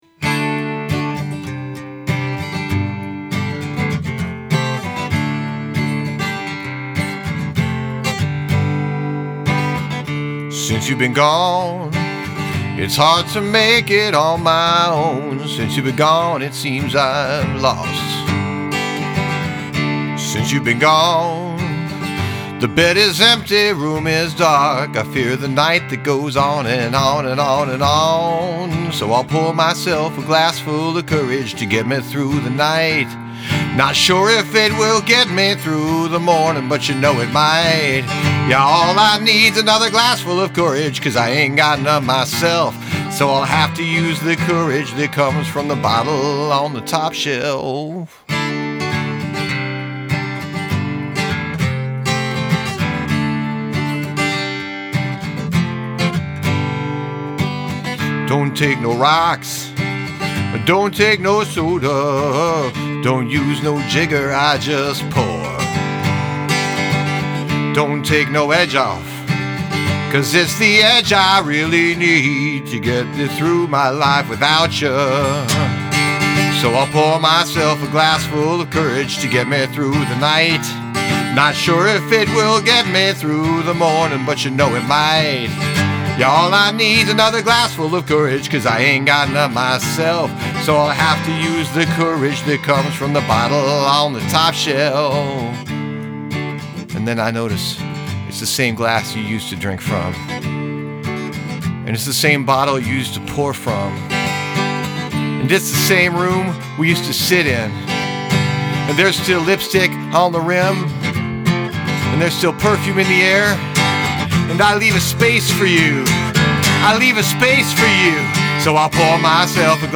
Must include a spoken word bridge